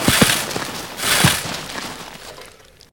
shovelclean.ogg